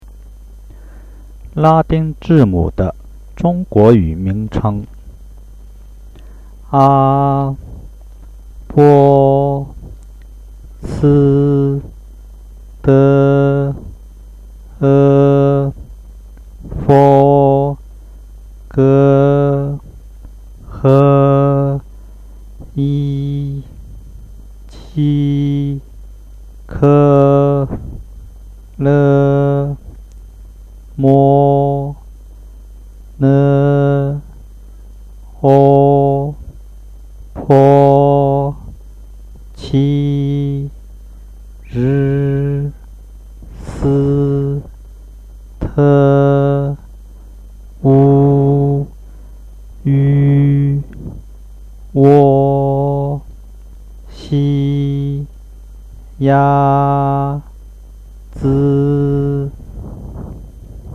The sounds and description of Roman letters by Chinese